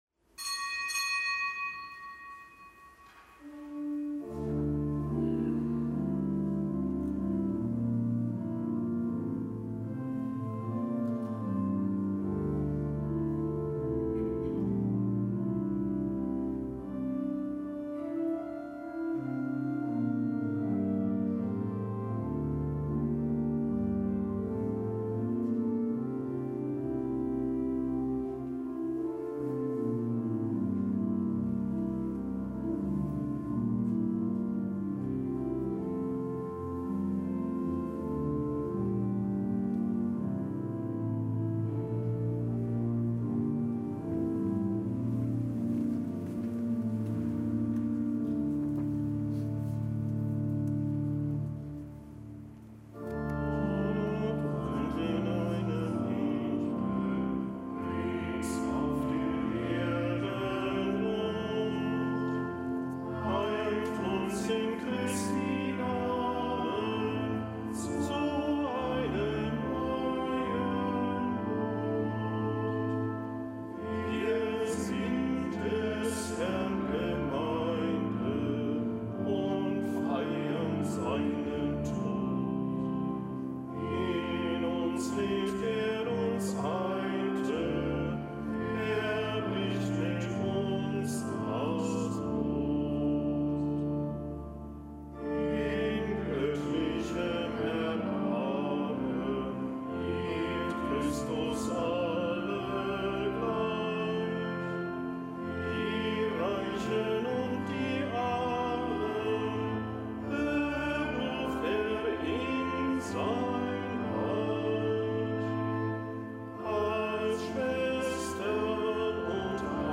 Kapitelsmesse am Montag der zweiten Woche im Jahreskreis
Kapitelsmesse aus dem Kölner Dom am Montag der zweiten Woche im Jahreskreis